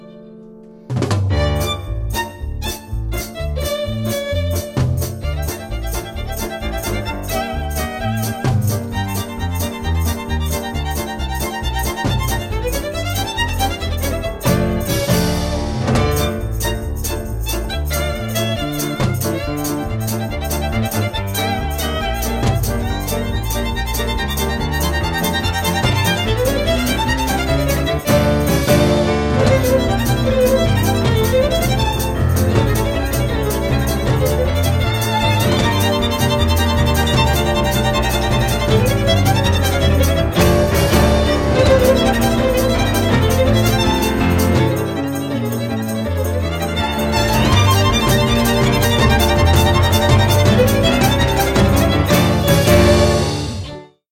le Violon – École de Musique & Harmonie Décinoises
Violon-Extrait-Czardas.mp3